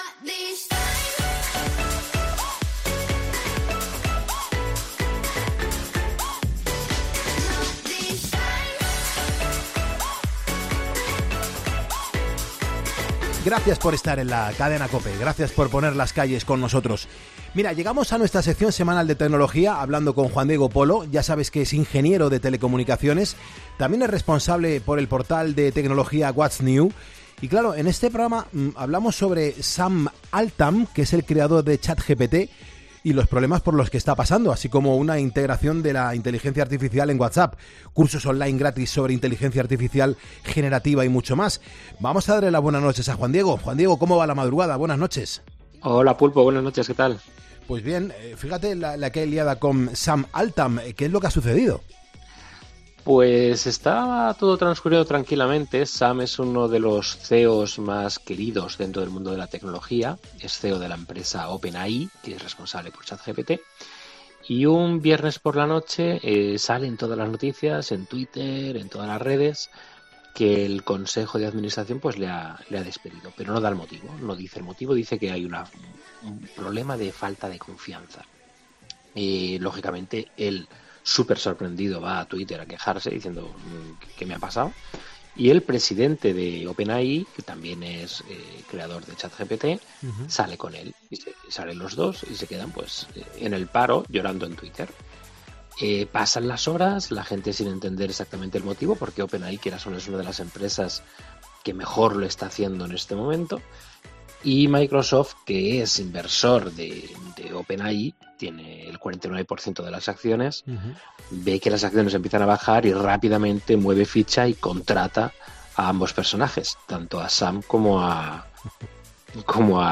El experto en tecnología